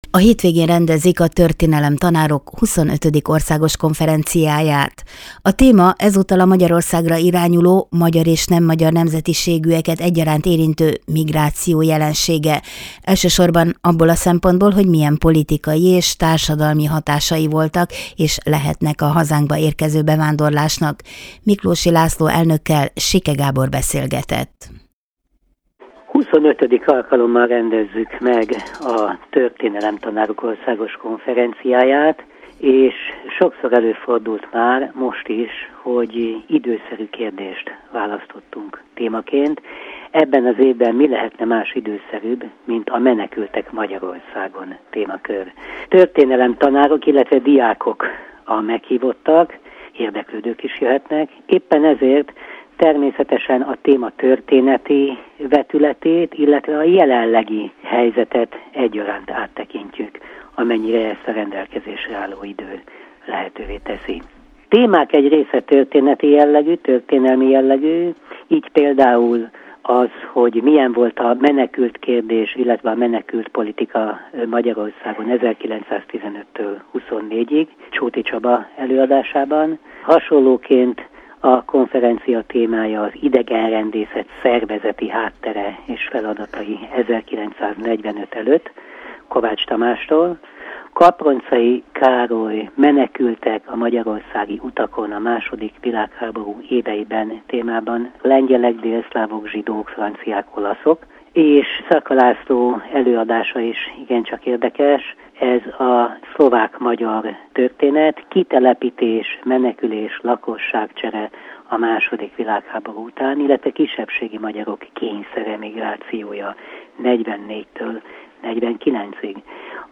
Előzetes interjú a Történelemtanárok 25. Országos Konferenciájáról